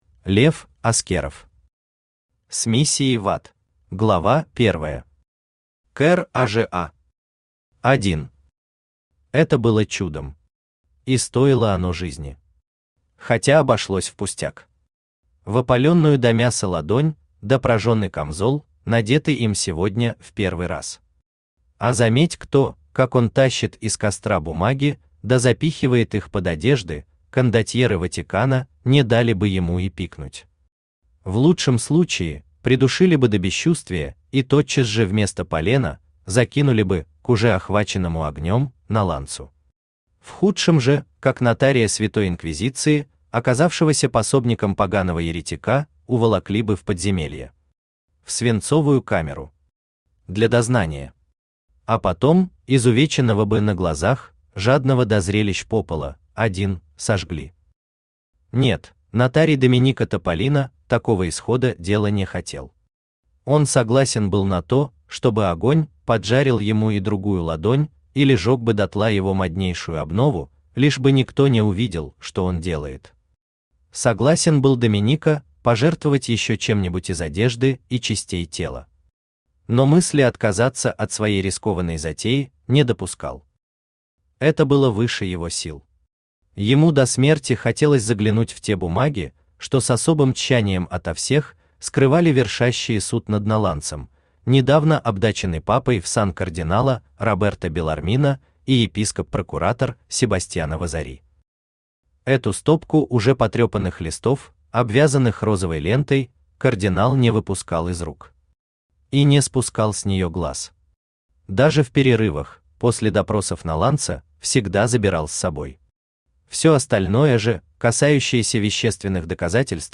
Аудиокнига С миссией в ад | Библиотека аудиокниг
Aудиокнига С миссией в ад Автор Лев Аскеров Читает аудиокнигу Авточтец ЛитРес.